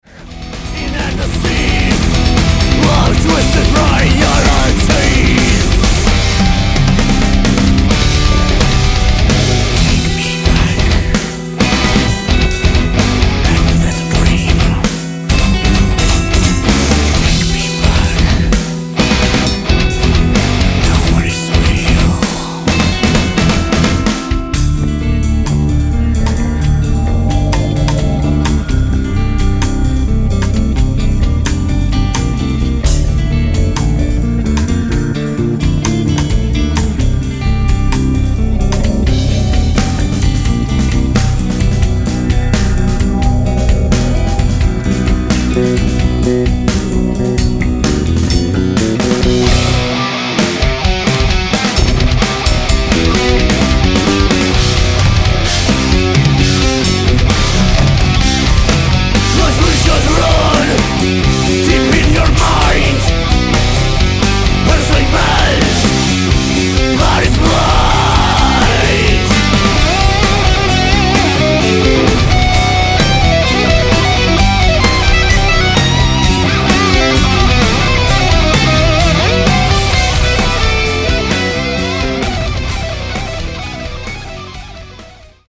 Nahrávací studio v Lipově audio / digital
Dám sem malinkou ukázečku, snad mě chlapi neukamenují za to.